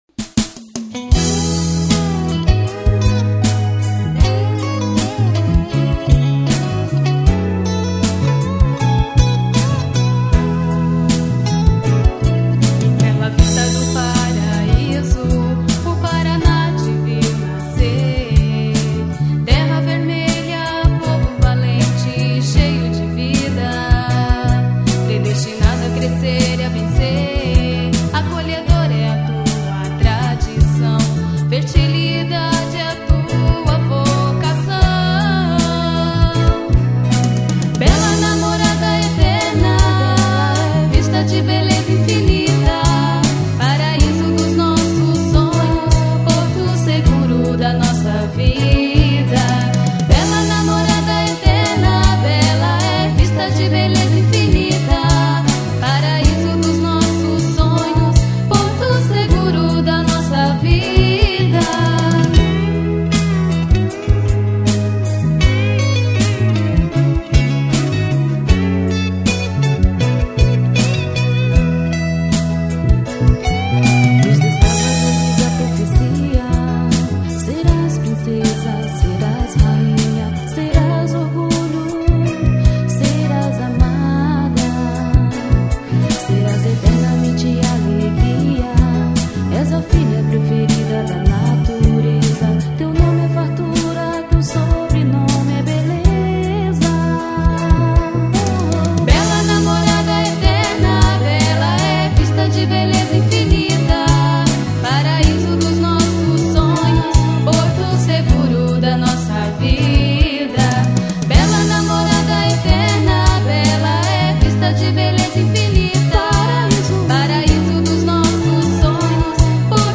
Hino_de_Bela_Vista_do_Paraíso_PR.mp3